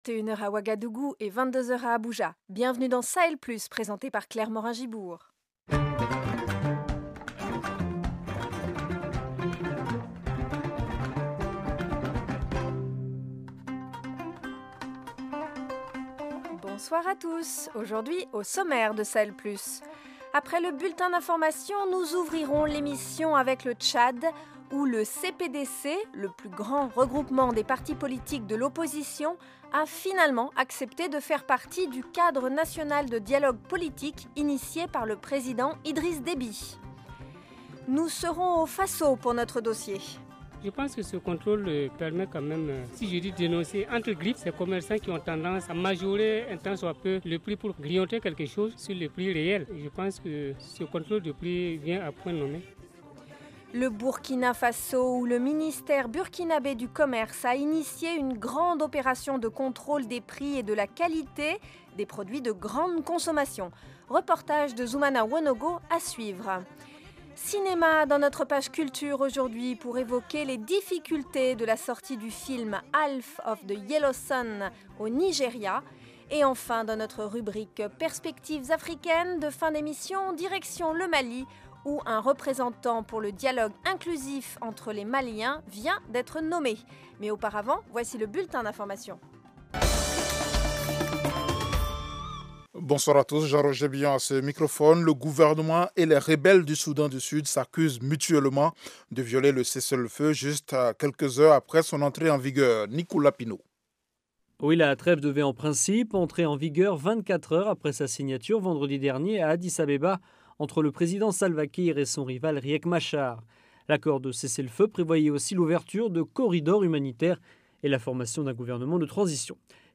Au programme : au Tchad, le CPDC, le plus grand regroupement des partis politiques de l’opposition, a finalement accepté de faire partie du Cadre National de Dialogue Politique initié par le président Idriss Déby. Dossier : au Burkina Faso, le ministère burkinabè du commerce a initié une grande opération de contrôle de la qualité et des prix des produits de grande consommation. Reportage